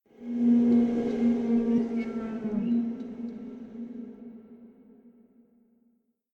scpcb-godot/SFX/Ambient/Forest/ambient3.ogg at master